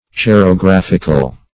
Search Result for " cerographical" : The Collaborative International Dictionary of English v.0.48: Cerographic \Ce`ro*graph"ic\, Cerographical \Ce`ro*graph"ic*al\, a. Of or pertaining to cerography.
cerographical.mp3